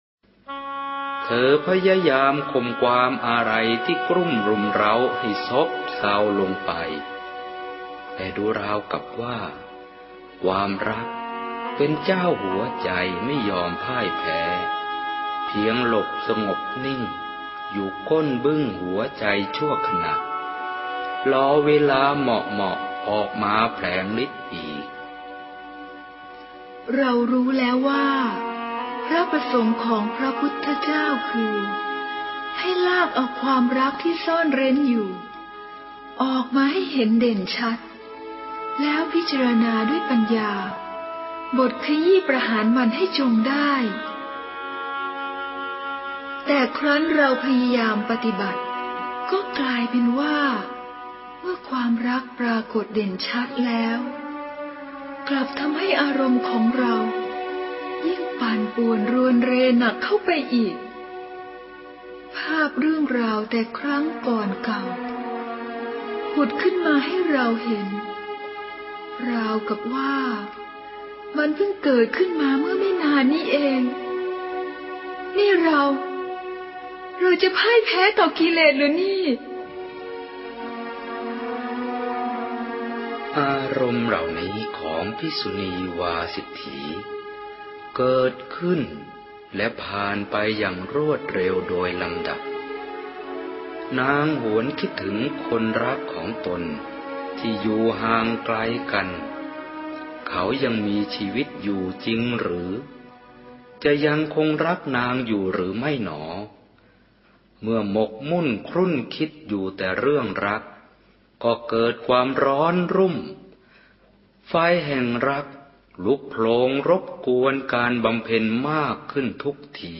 กามนิต วาสิฏฐี - ละครเสียงอิงหลักธรรม - เสียงธรรม : ธรรมะไทย